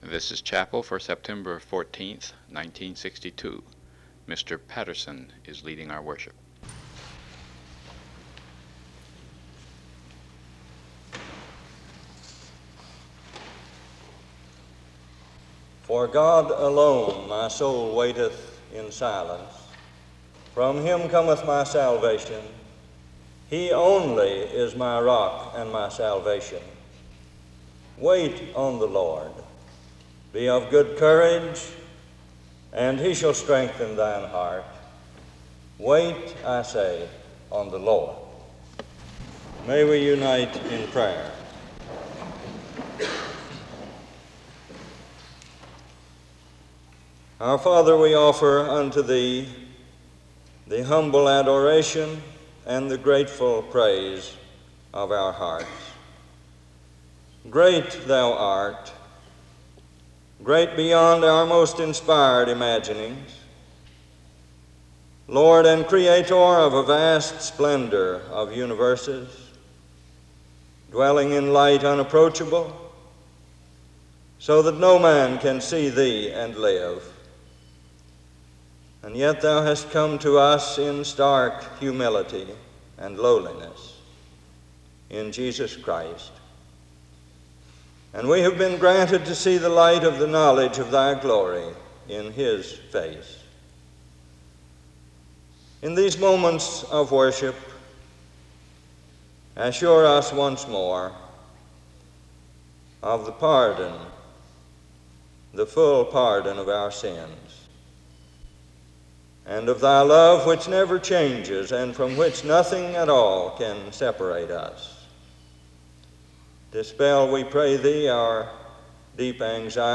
The service begins with a passage of scripture reading and prayer from 0:15-3:30.